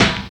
20 SNARE 3.wav